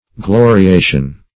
Search Result for " gloriation" : The Collaborative International Dictionary of English v.0.48: Gloriation \Glo`ri*a"tion\, n. [L. gloriatio, from gloriari to glory, boast, fr. gloria glory.